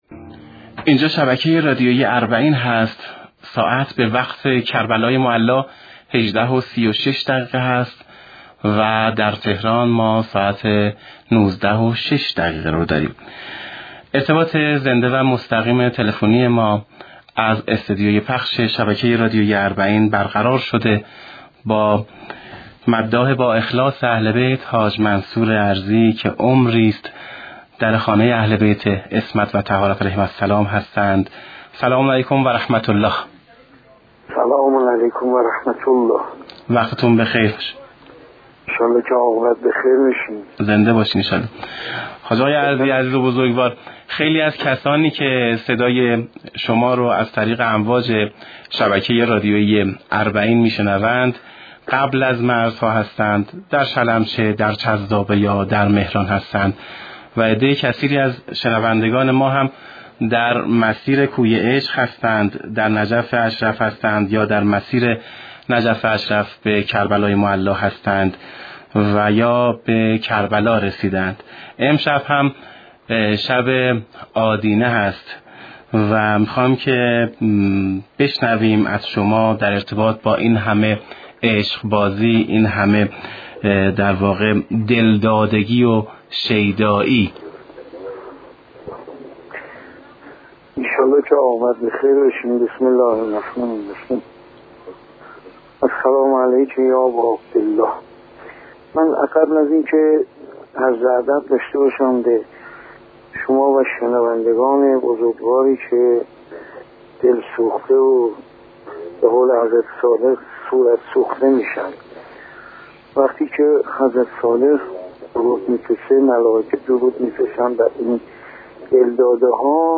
در گفتگو با رادیو اربعین